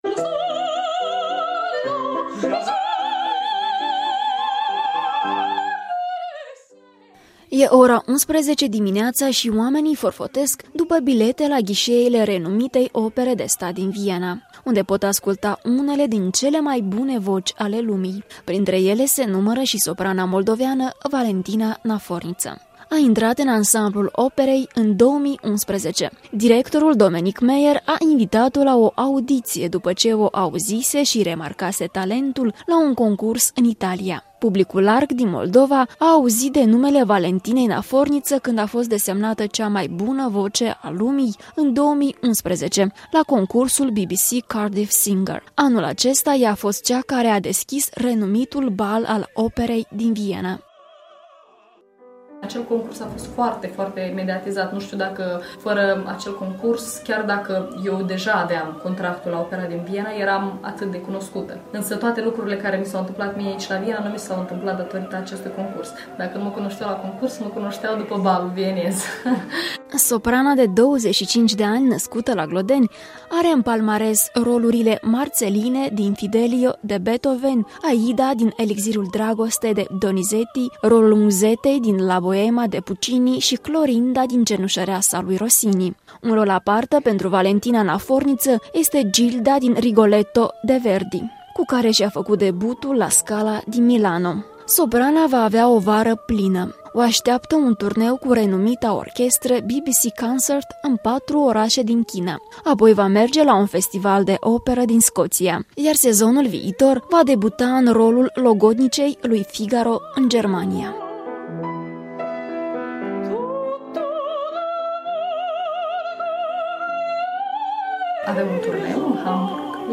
La Viena, în dialog cu soprana Valentina Naforniță